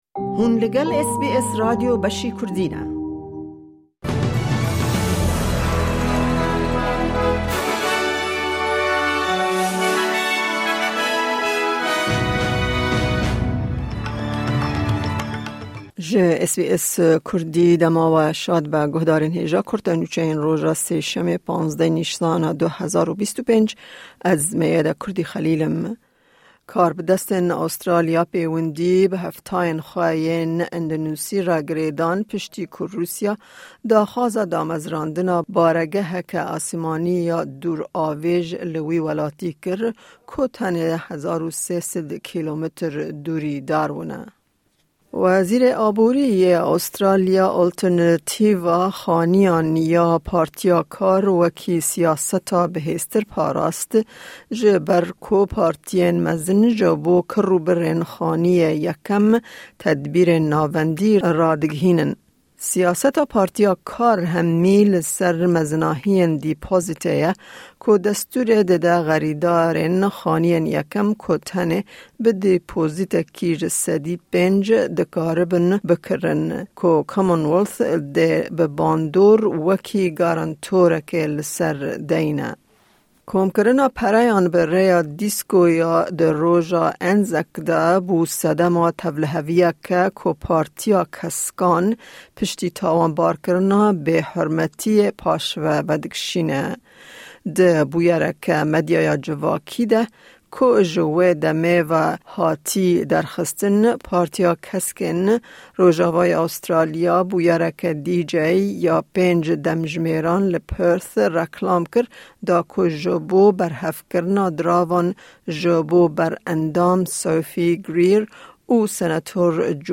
Kurte Nûçeyên roja Sêşemê 8î Nîsana 2025